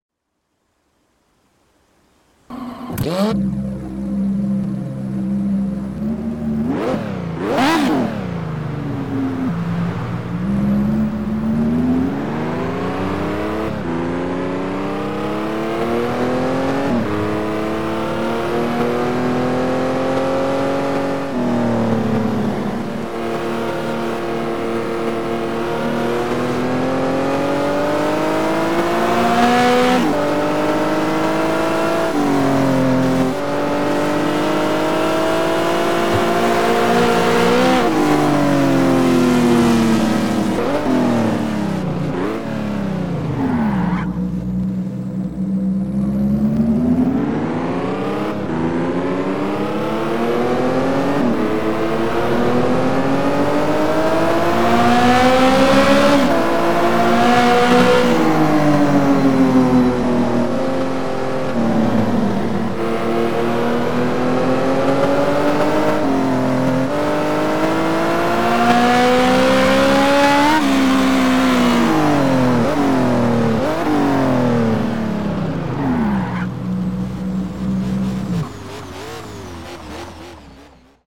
- Ferrari F430